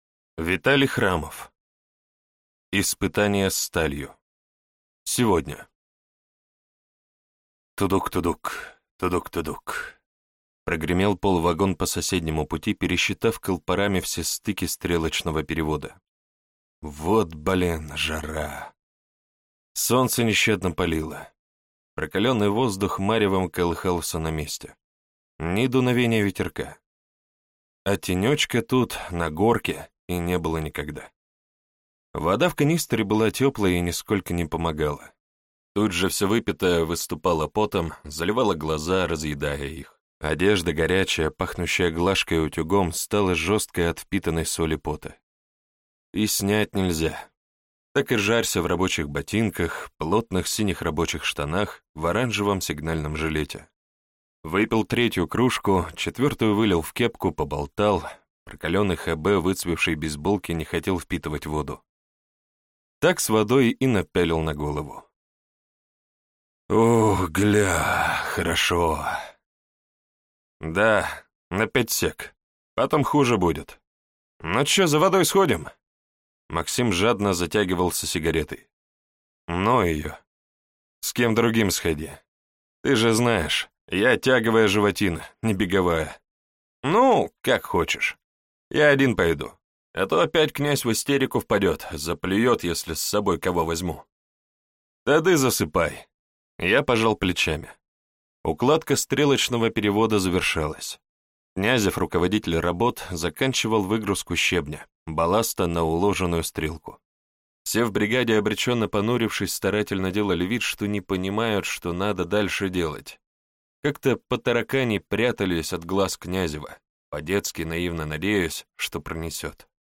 Аудиокнига Испытание сталью | Библиотека аудиокниг